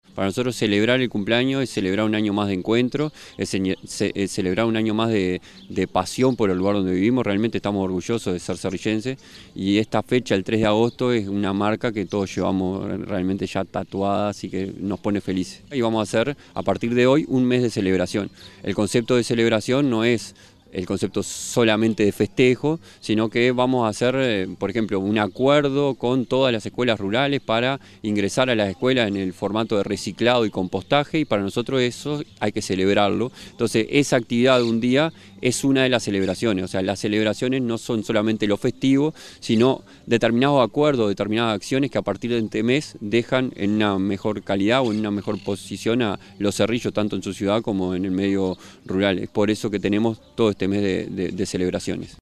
En presencia del Presidente de la República, Dr. Luis Lacalle Pou, el Secretario de Presidencia, Álvaro Delgado, el Secretario General de la Intendencia de Canelones, Dr. Esc. Francisco Legnani, la Pro Secretaria General, As. Soc. Silvana Nieves, el Alcalde del Municipio de Los Cerrillos, Prof. Rodrigo Roncio, integrantes de la Asociación Histórica de Los Cerrillos, autoridades nacionales, departamentales y locales, instituciones educativas, vecinas y vecinos, se realizó el acto conmemorativo del 126° aniversario de la ciudad de Los Cerrillos, en la plaza José Batlle y Ordóñez.